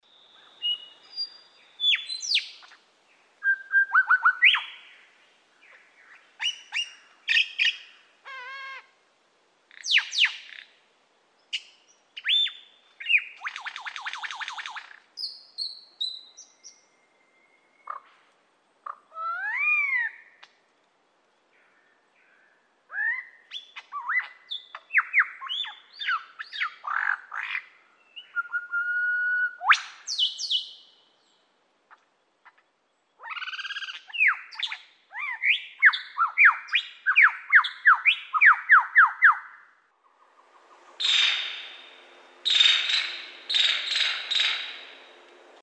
Det er kratfuglene, som kun har nogle få temaer (06), og lyrefuglene, hvis sang er mere varieret